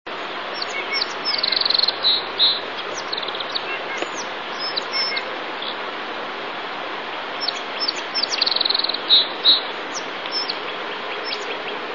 Song Sparrow
sparrowsong307.wav